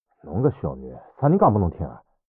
三楼/囚室/肉铺配音偷听效果处理